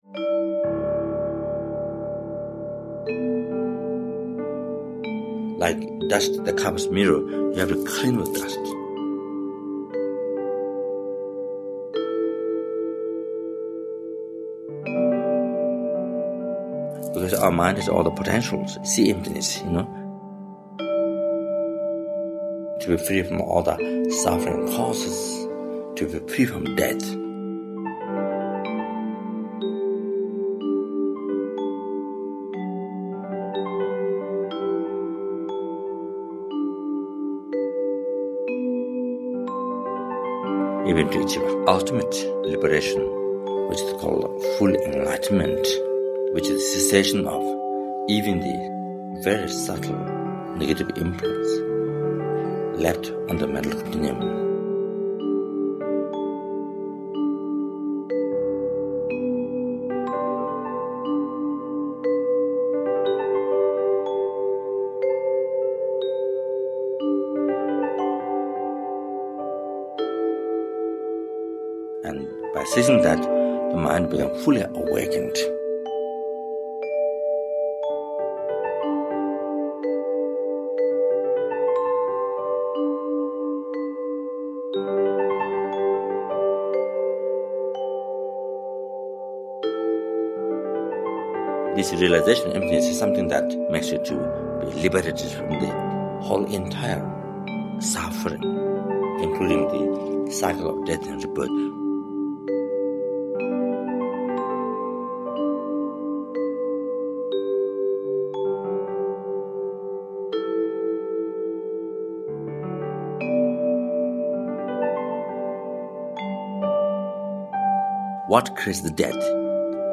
вибрафон, яванский гонг, античная тарелочка
рояль